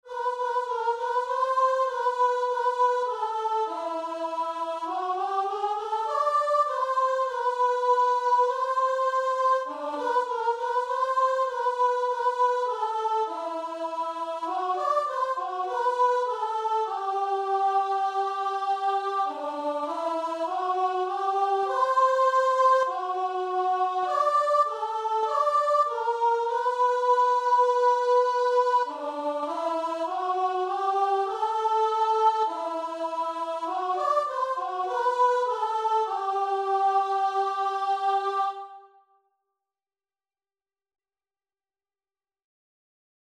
Christian Christian Guitar and Vocal Sheet Music He Keeps Me Singing
Free Sheet music for Guitar and Vocal
4/4 (View more 4/4 Music)
G major (Sounding Pitch) (View more G major Music for Guitar and Vocal )
Classical (View more Classical Guitar and Vocal Music)